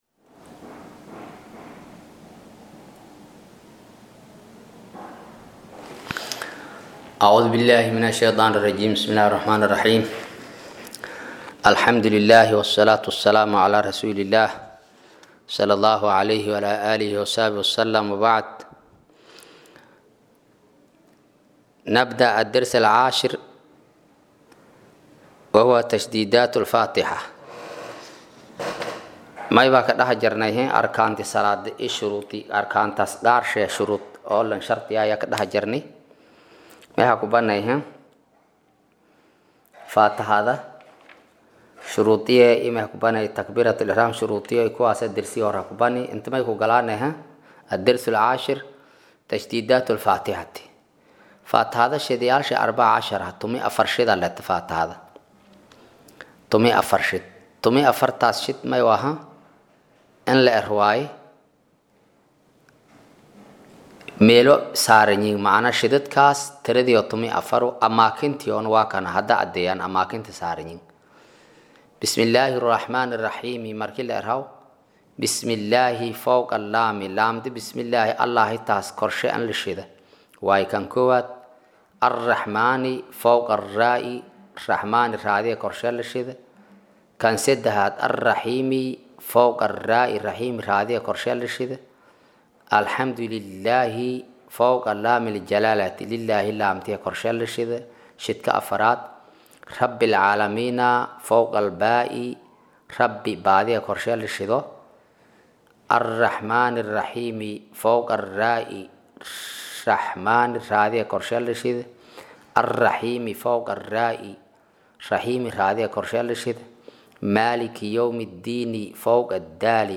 Maqal:- Casharka Safiinatu Najaa “Darsiga 10aad”